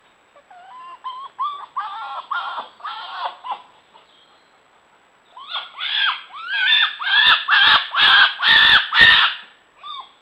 An excited vixen submissively screaming during the mating season. The call varies in intensity and is more often heard towards the end of the breeding season when unmated vixens haven't been mated. Note that the first part is very similar to the winter bark, before it screaming ensues.